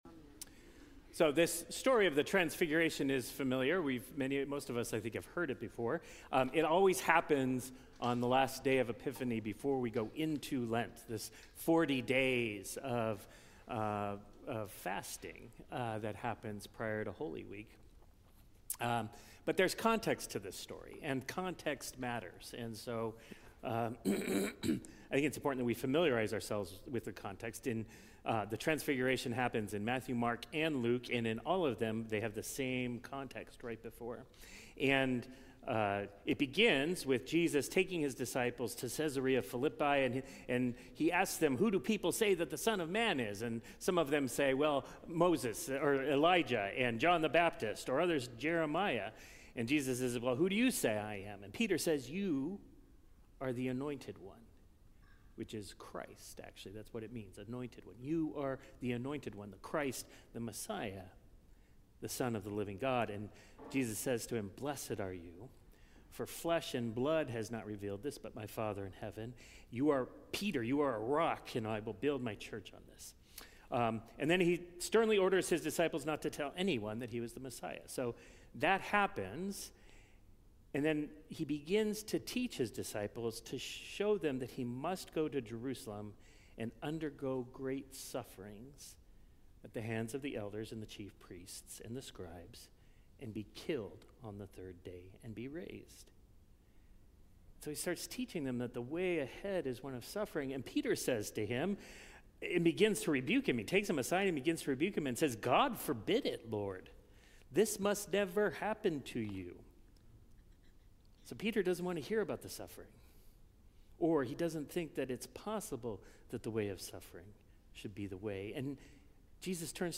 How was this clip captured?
The Last Sunday after the Epiphany